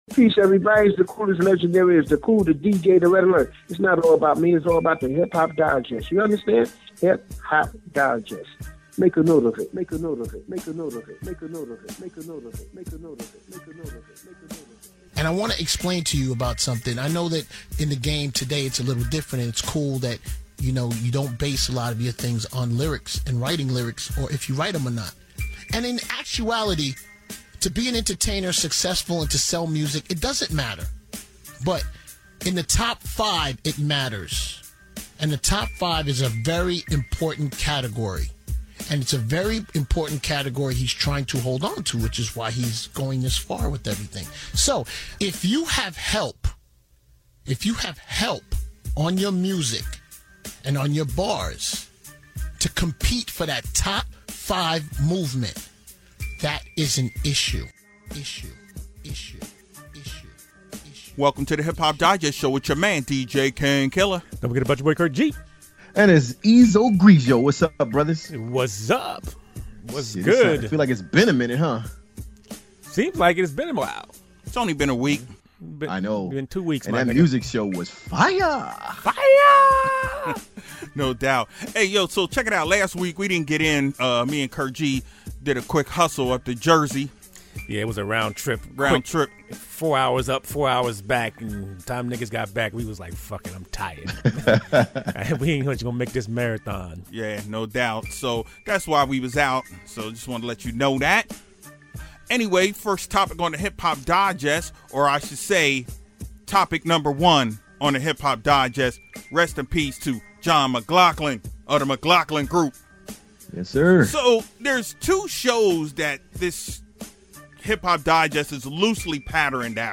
There's quite a few topics that are definitely on point this week, but the subject of Ghostwriting struck a cord. We had a SERIOUS debate about the issue.